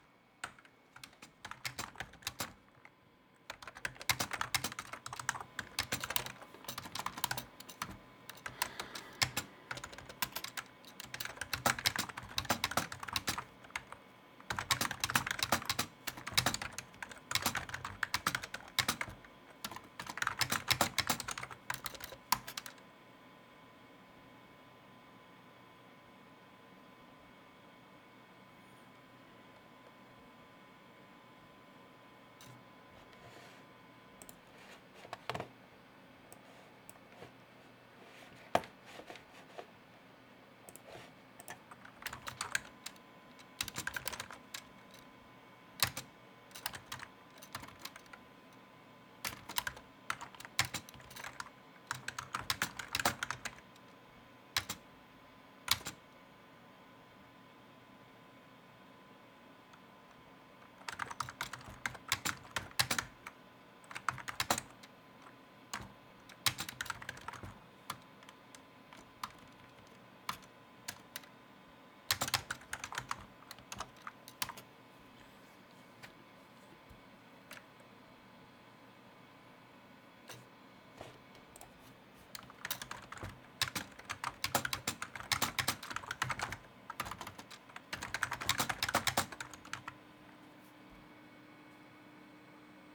keyboardmouse
click computer foley keyboard mouse recording typing writing sound effect free sound royalty free Sound Effects